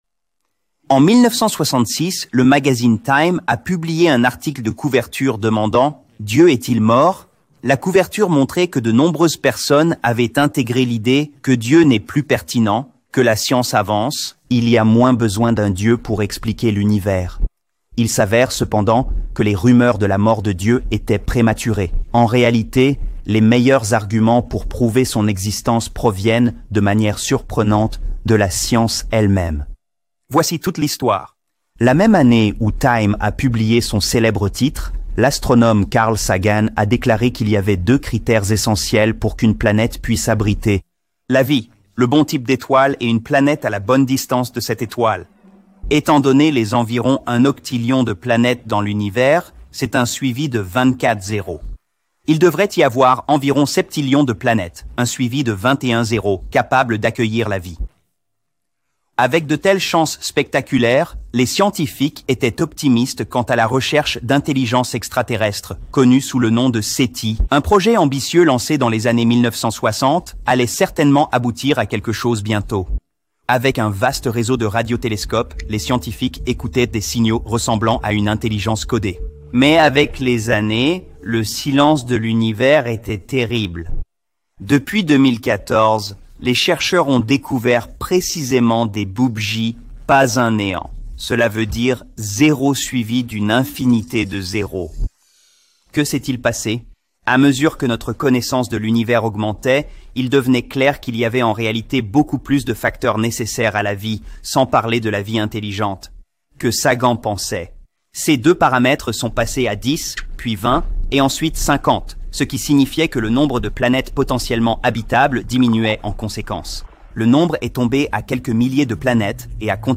Description: Dans cette vidéo, Eric Metaxas parle de la manière dont de nombreux grands athées du monde entier soutiennent l'existence de Dieu.